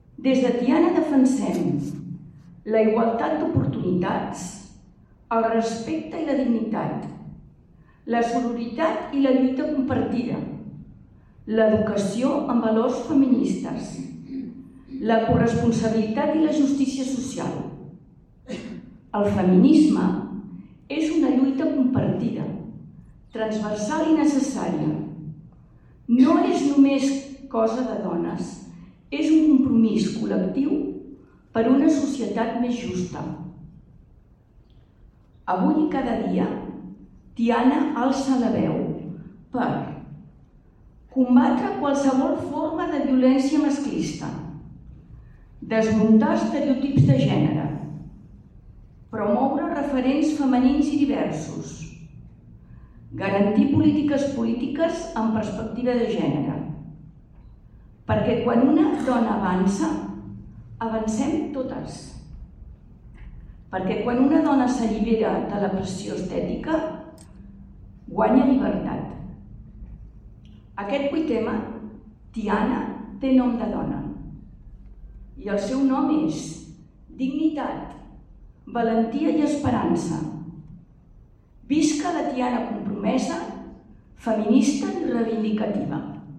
La Sala Albéniz ha acollit aquest dissabte 7 de març a les dotze del migdia l’acte institucional del 8M a Tiana, que enguany ha posat el focus en la pressió estètica i el cos.